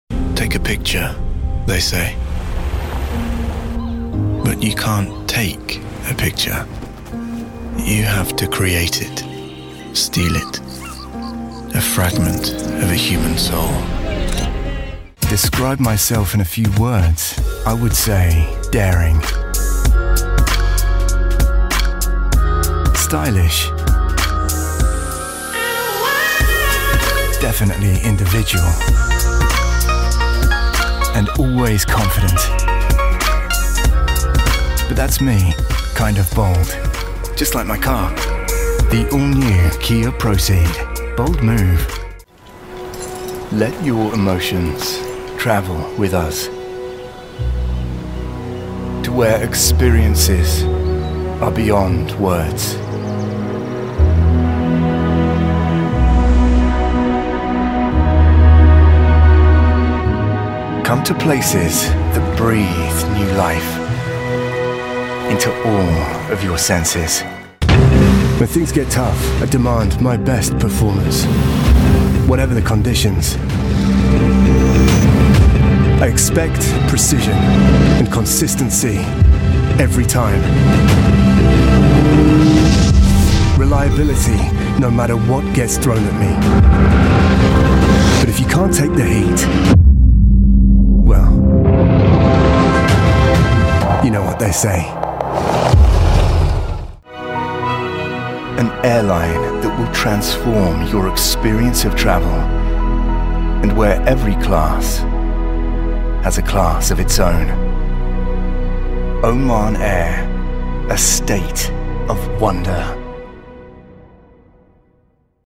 dunkel, sonor, souverän
Mittel minus (25-45)
Commercial (Werbung)